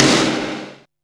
T SNARE 1.wav